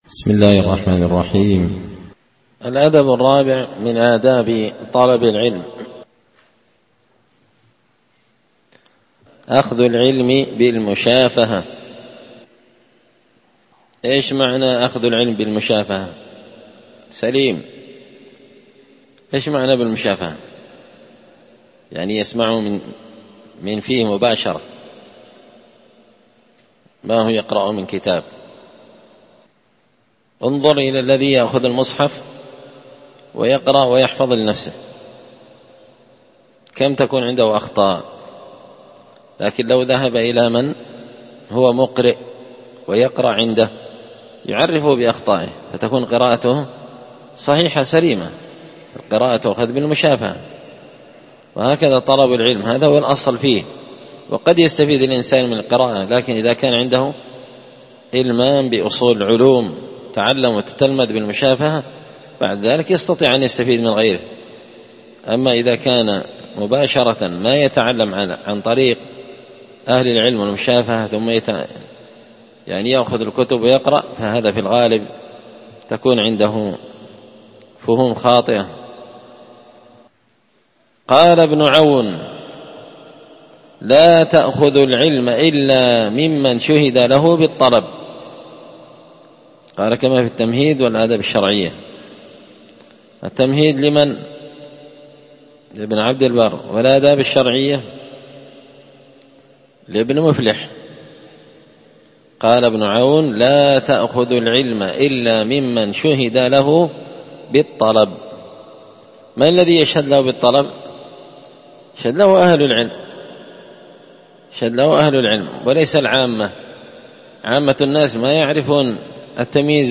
الدرس الخامس (5) الأدب الرابع أخذ العلم بالمشافهة